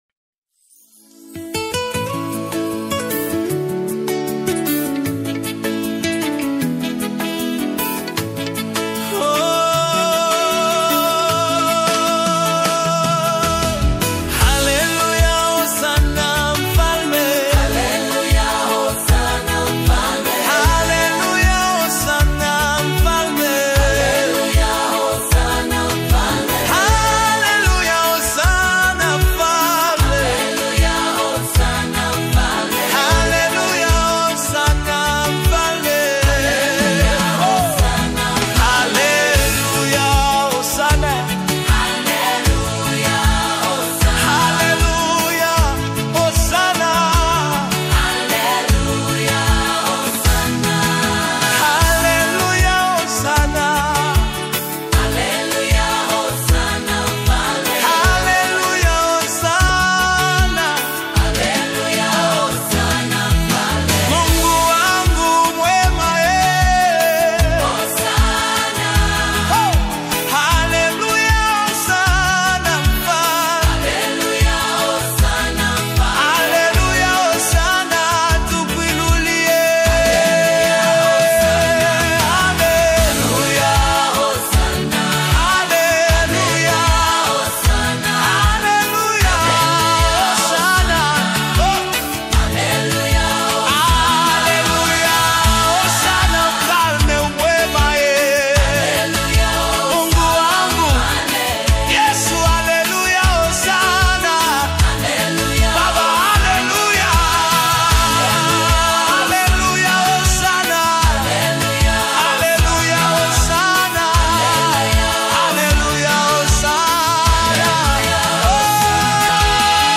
The rhythmically infectious and vocally communal track